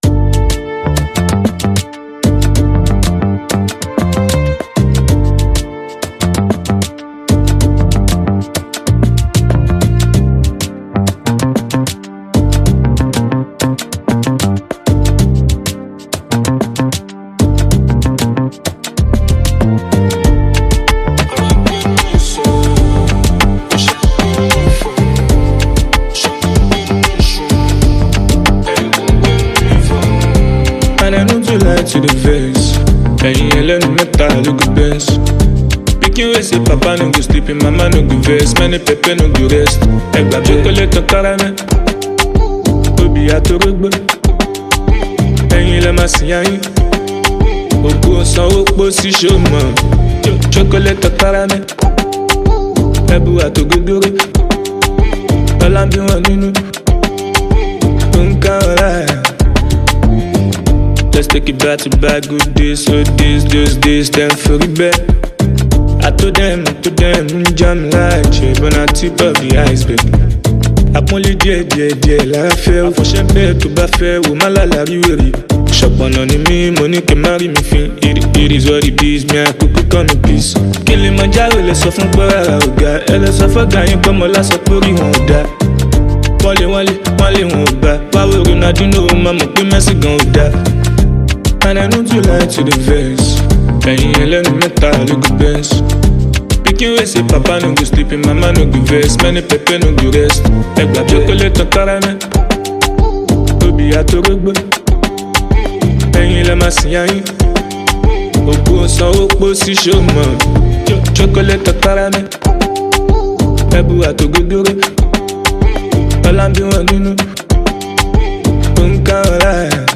Phenomenal talented Nigerian rap artiste